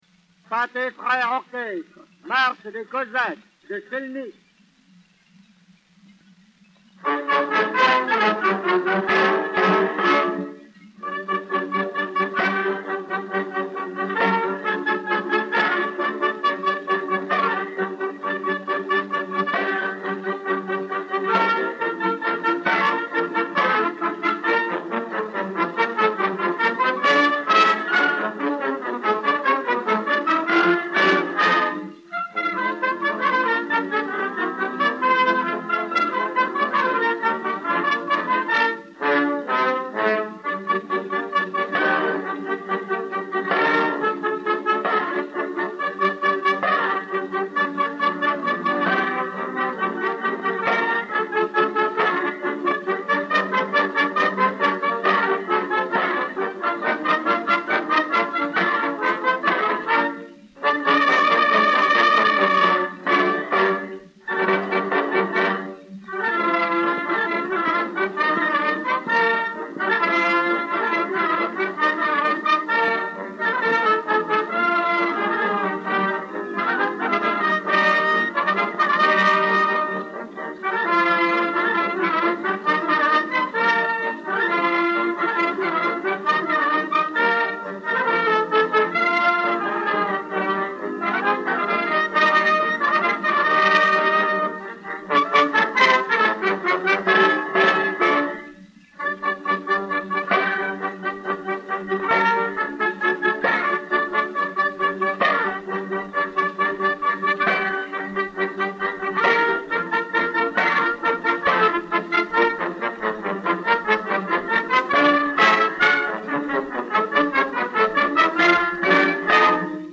Orchestre Pathé frères
Pathé saphir 90 tours n° 6192, enr. vers 1910